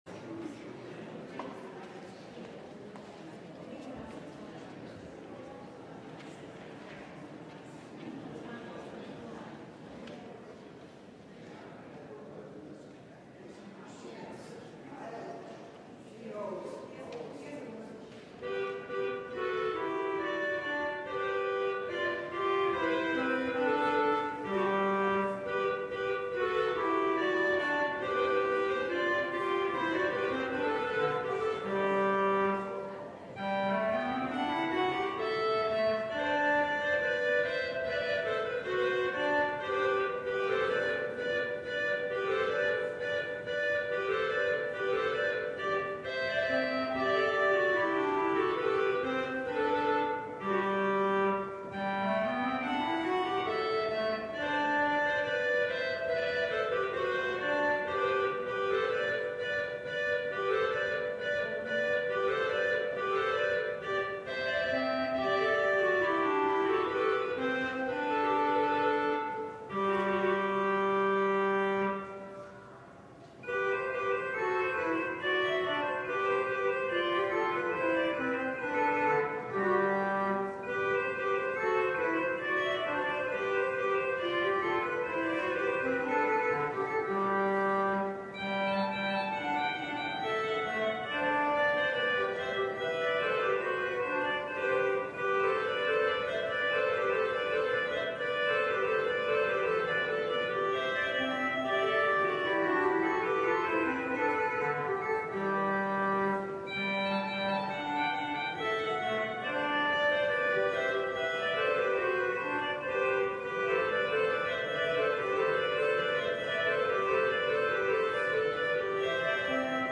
LIVE Midday Worship Service - The Women of the Genealogy: Mary
We will also finish the service by singing the Hallelujah Chorus together.